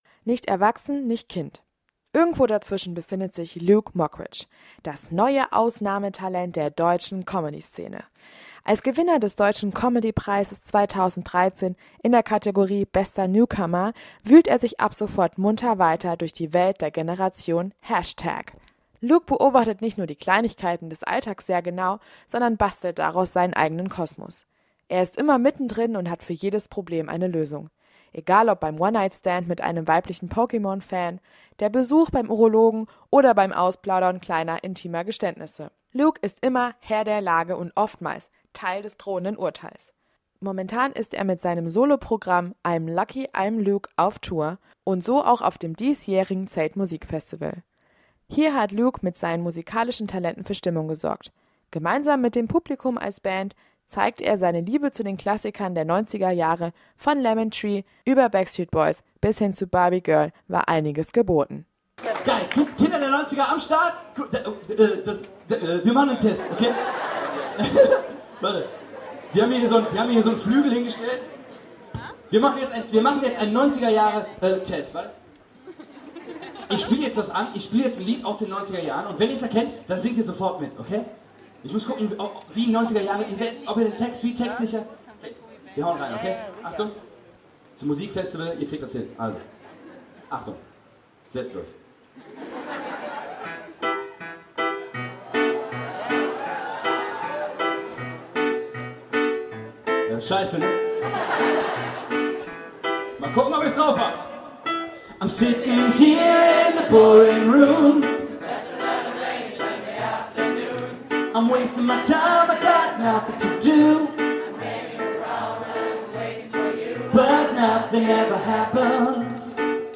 Gebauter Beitrag